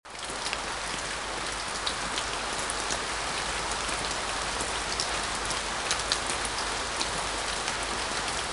下雨 | 健康成长
rain.mp3